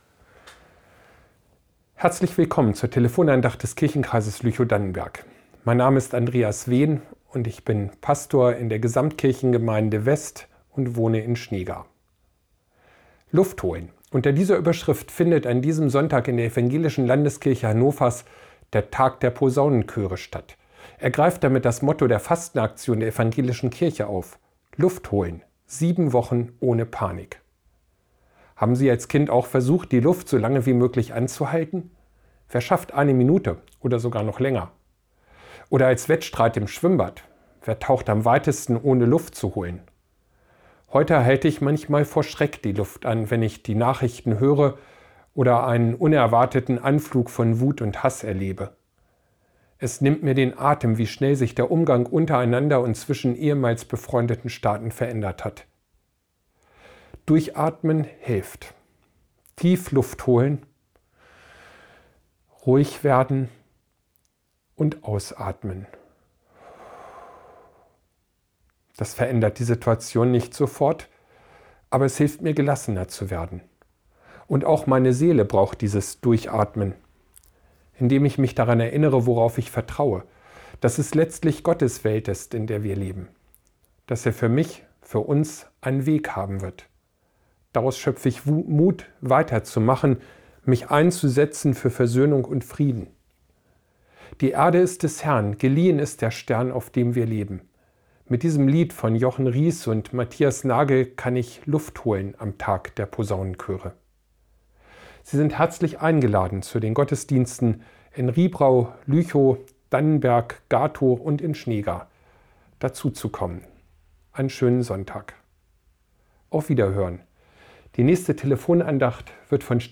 Telefon-Andacht vom 30.03.2025